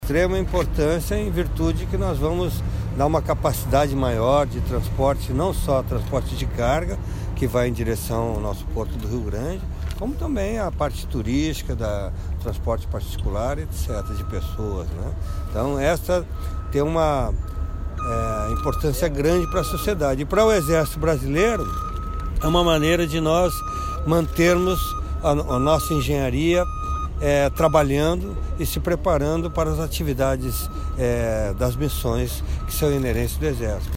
Um dos benefícios será melhorar o acesso ao Porto de Rio Grande, localizado na região Sul do Estado, como explica o General